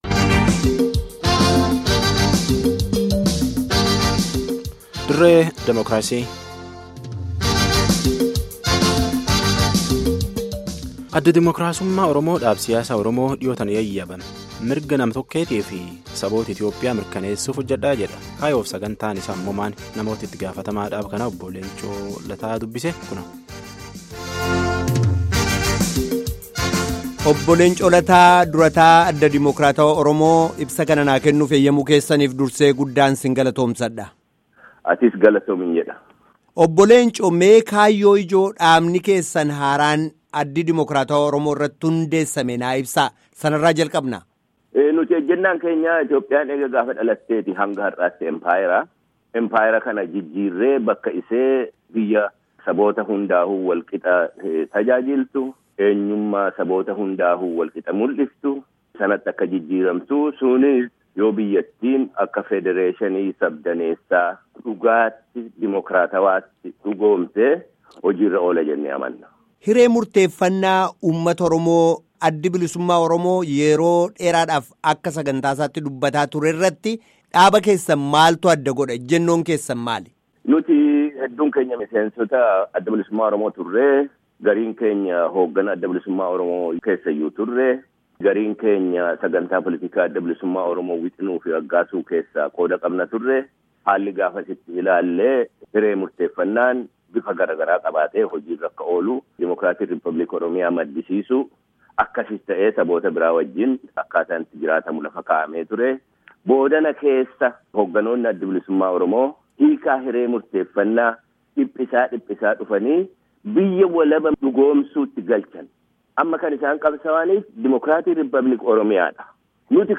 Obbo Leencoo Lataa Waliin Marii geggeeffame caqasa